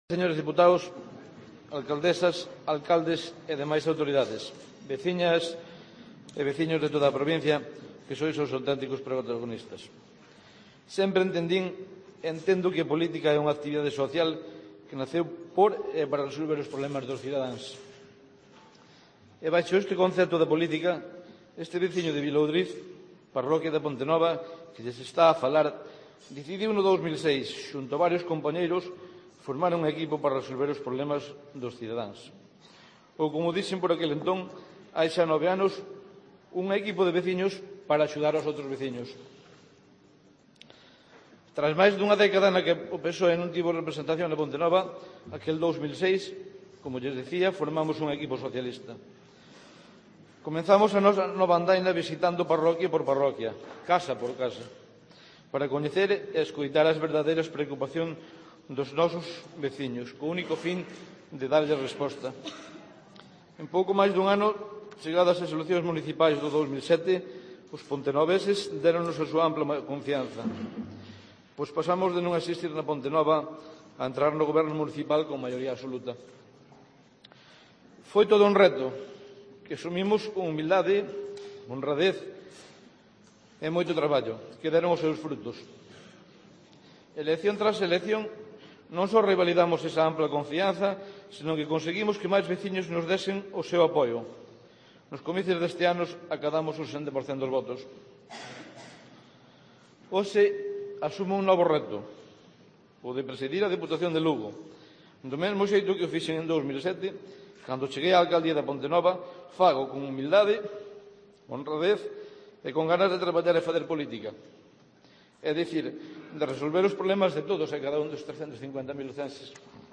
Darío Campos (Discurso de toma de posesión)